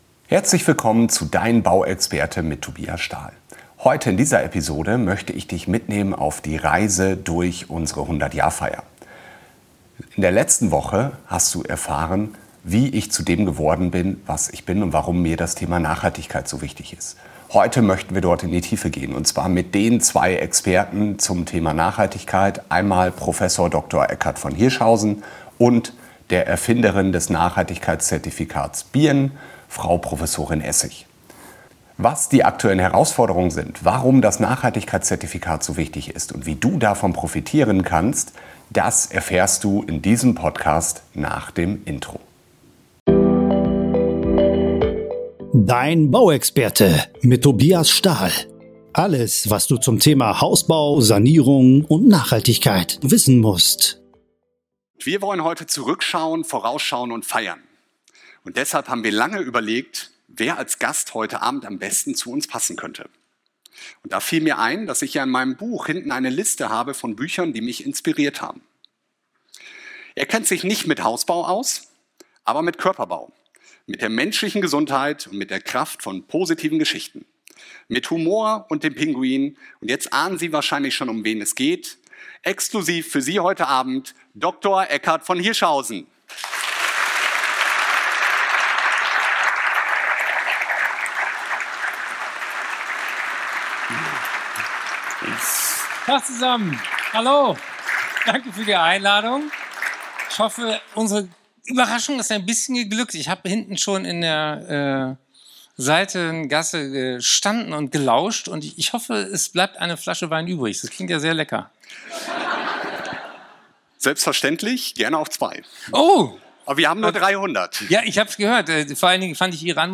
Als besonderen Gast haben wir Dr. Eckart von Hirschhausen eingeladen.